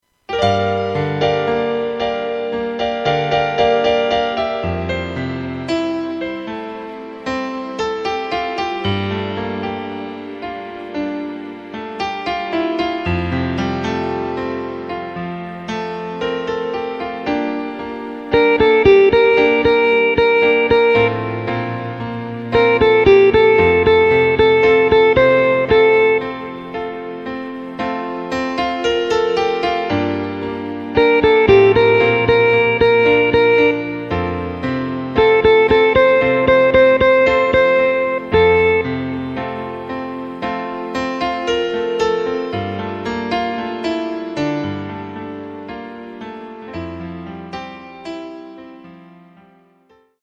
Takt:          4/4
Tempo:         114.00
Tonart:            Am
Cover aus dem Jahr 2012!